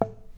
Road kill + sound effects